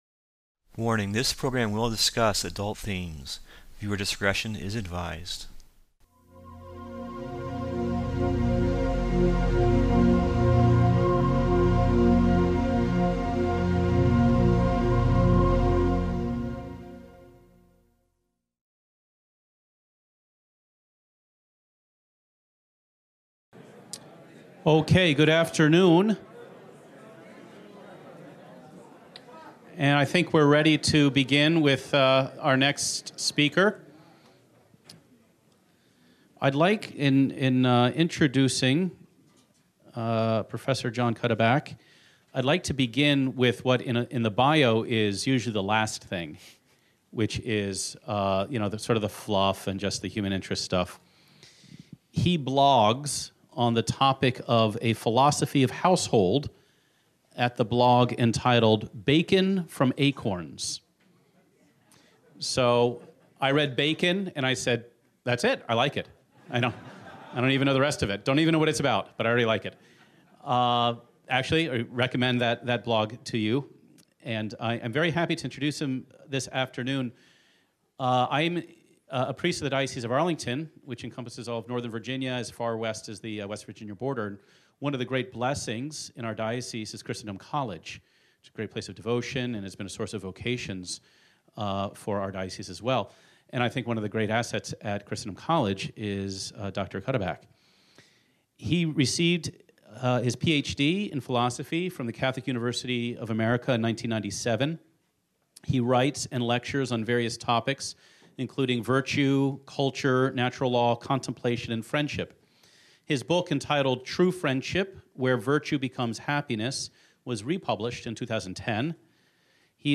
at the 2015 Courage Conference at the University of St. Mary of the Lake in Mundelein, Il.